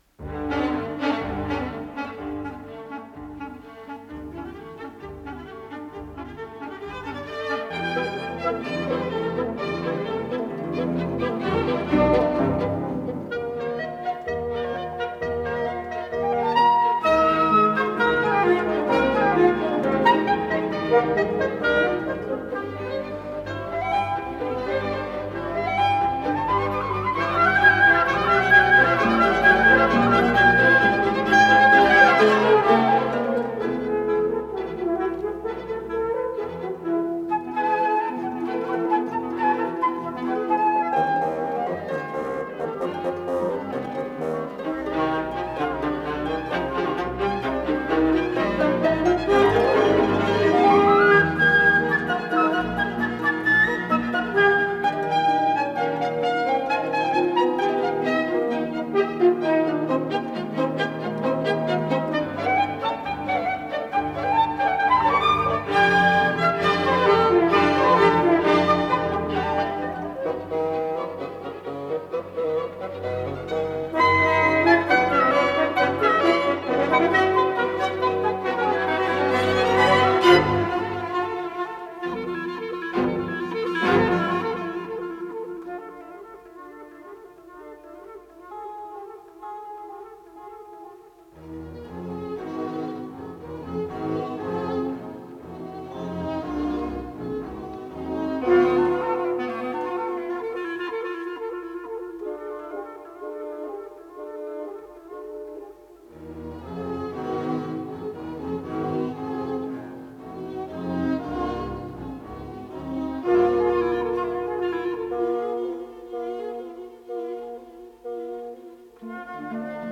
с профессиональной магнитной ленты
ПодзаголовокИз цикла "Деревенские концерты" ("Развлечения") для малого оркестра, соль мажор, соч. 32 №3
Аллегро джиокозо
ИсполнителиАнсамбль солистов Государственного академического оркестра СССР
Дирижёр - Владимир Вербицкий
ВариантДубль моно